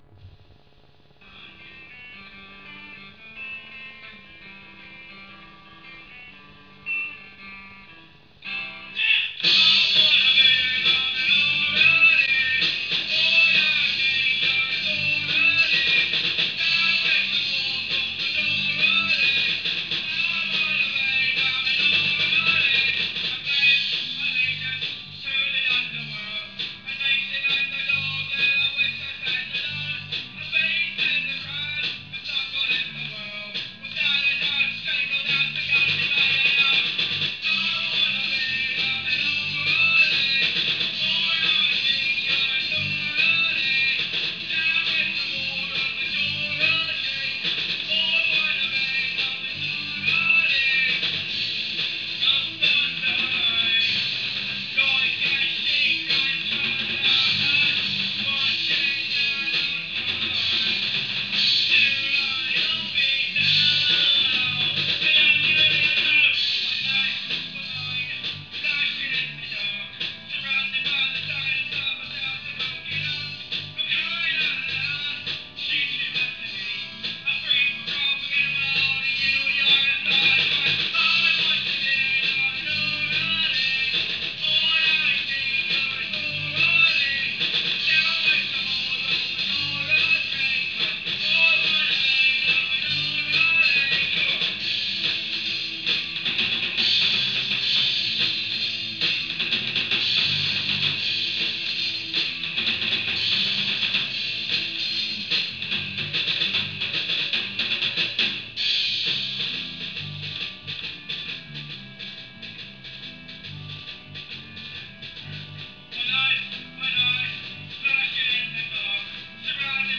We toned it down a bit.